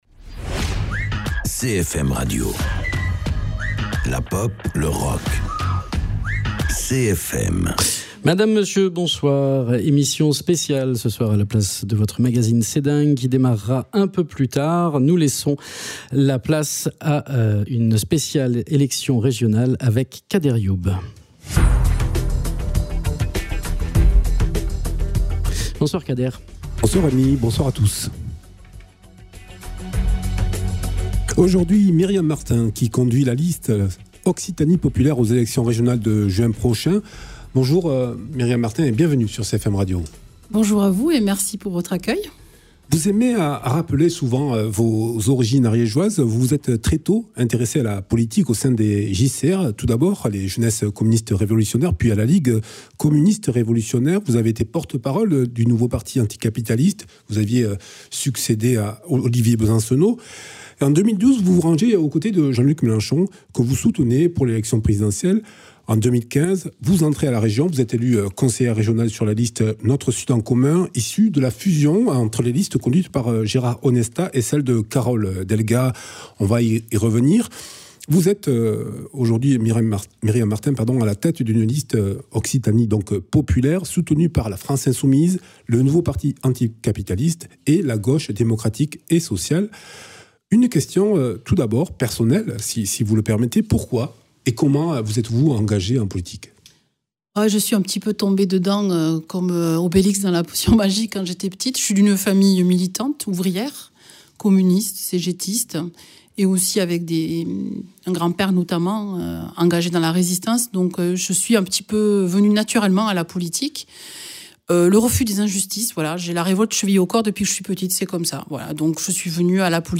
Régionales 2021 - Myriam Martin, tête de liste Occitanie Populaire pour les élections régionales de juin prochain et soutenue par La France Insoumise (LFI), le Nouveau Parti Anticapitaliste (NPA) et la Gauche démocratique et sociale (GDS), nous présente son projet pour la région Occitanie. Retrouvez également cette interview en vidéo sur notre page facebook ou notre chaine Youtube.
Invité(s) : Myriam Martin, tête de liste Occitanie Populaire